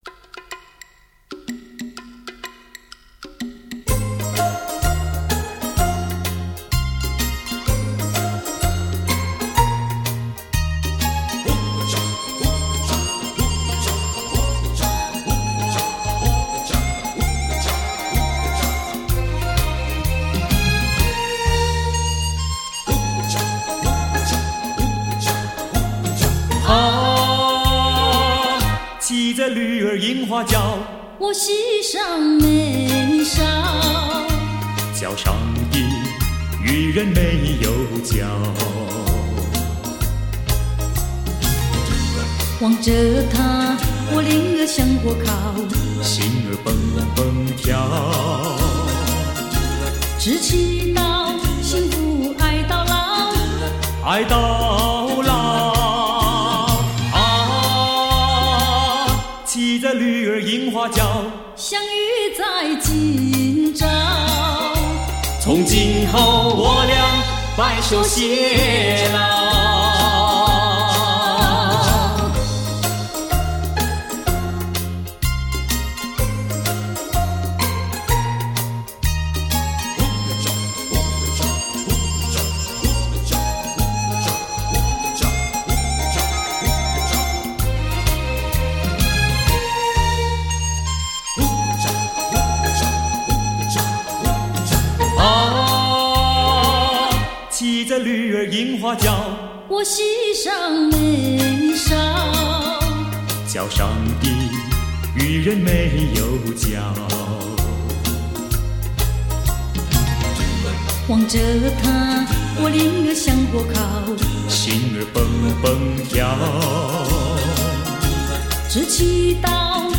数码调音录制